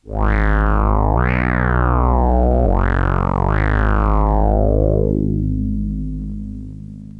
No effects were used and the mixer tone controls were set in neutral positions.
Deep sound with a classic slow analogue filter sweep low_wow.mp3
low_wow.wav